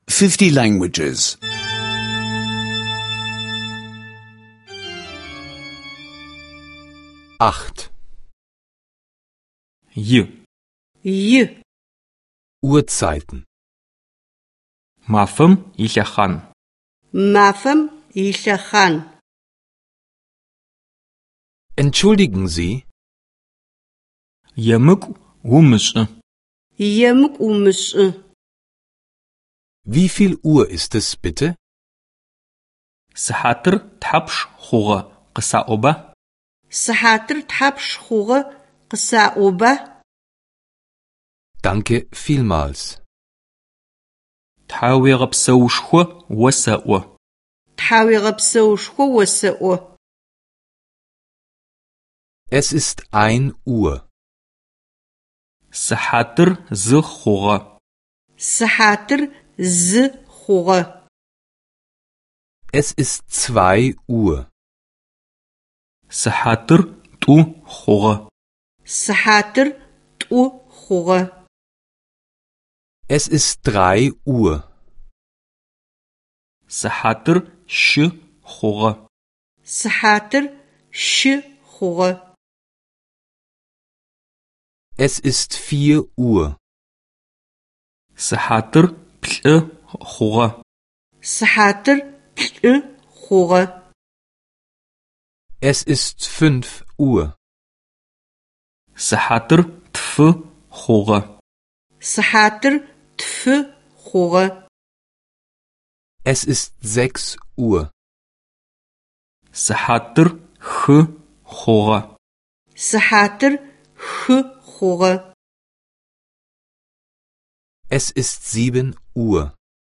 Adygeanischer Audio-Lektionen, die Sie kostenlos online anhören können.